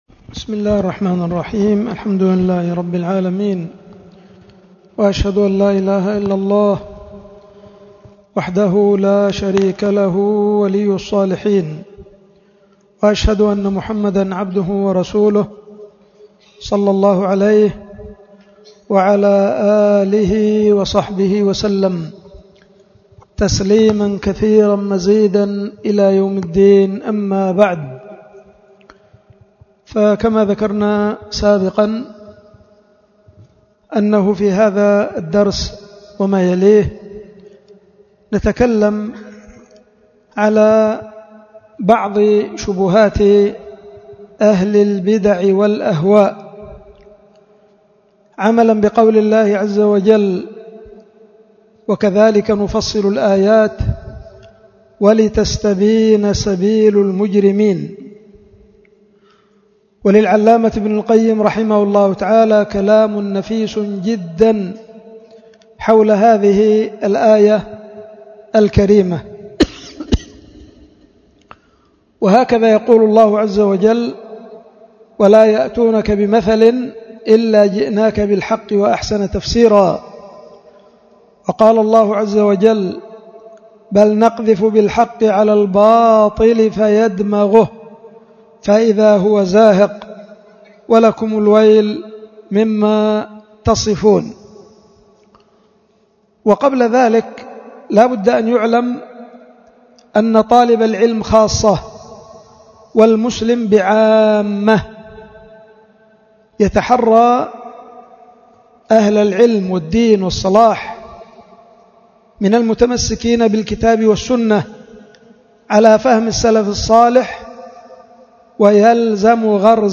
محاضرة
القيت في دار الحديث بمدينة دار السلام العلمية يختل المخا